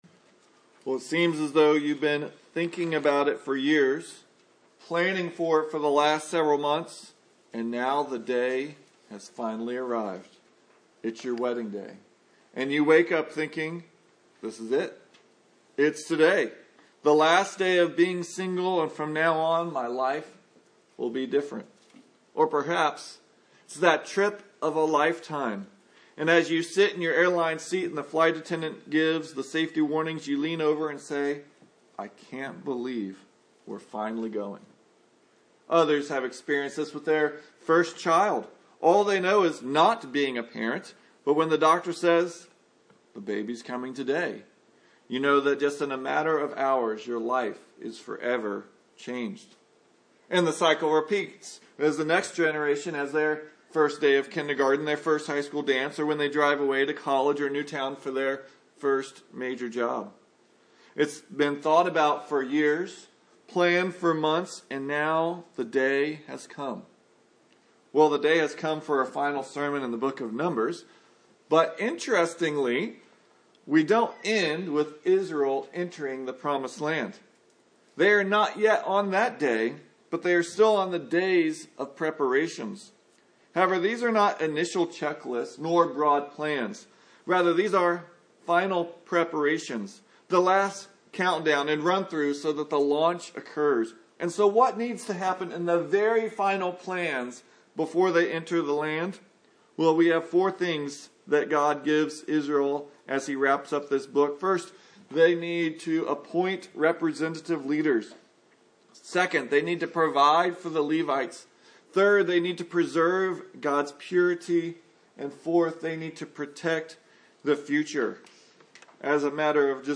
Numbers 34:16-36:13 Service Type: Sunday Morning Numbers does not end with Israel entering the Promised Land.